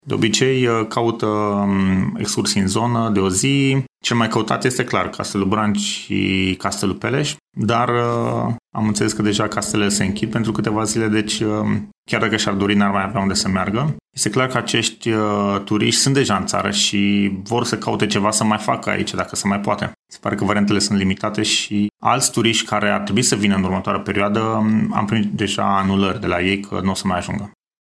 operator turism